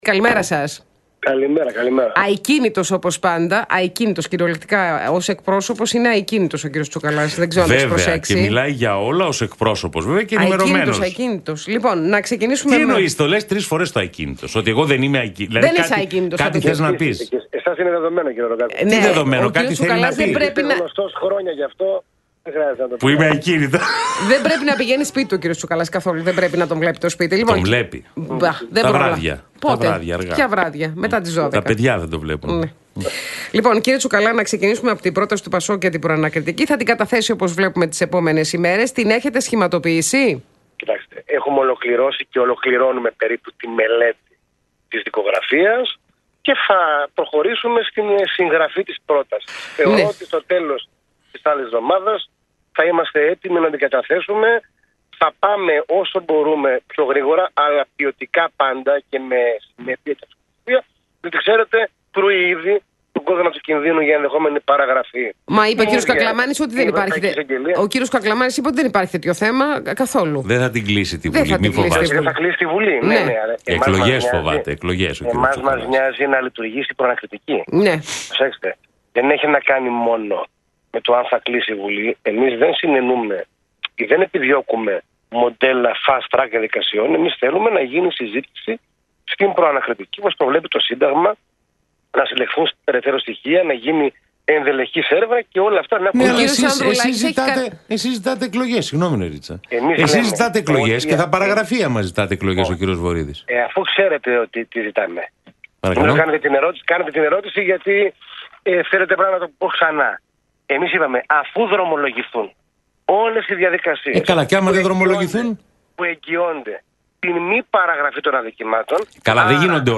Τσουκαλάς στον Realfm 97,8: Με το σκάνδαλο του ΟΠΕΚΕΠΕ η ΝΔ βάζει σε μνημόνιο τον πρωτογενή τομέα